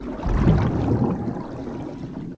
diving_swim_loop.ogg